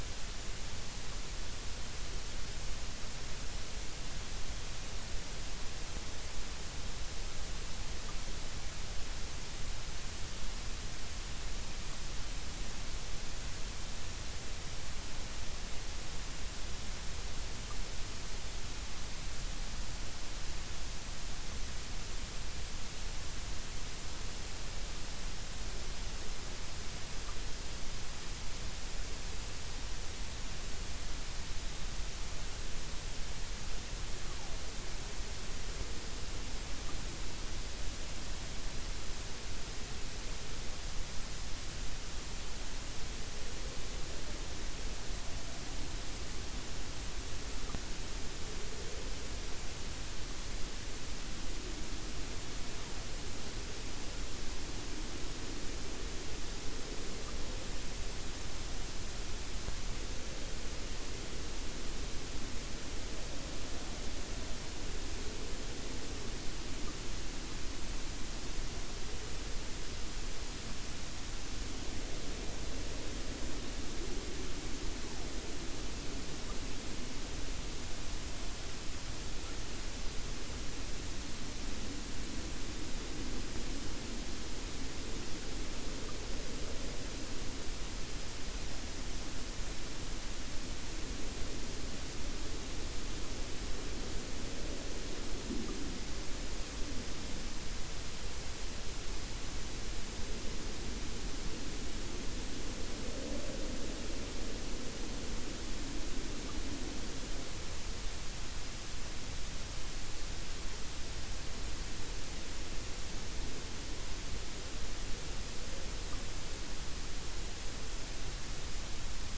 Random MP3 space sound